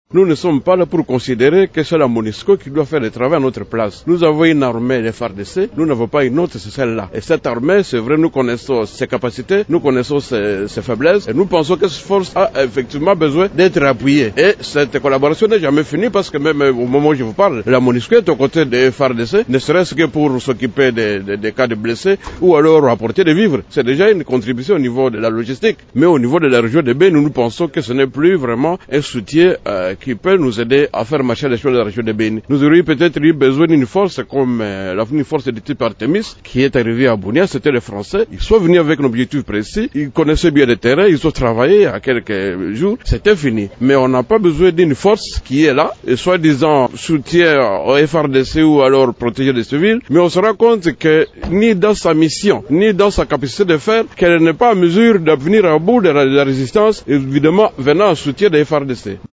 Vous pouvez écouter Shadrack Baitsura Musowa, l’un des élus du Nord-Kivu :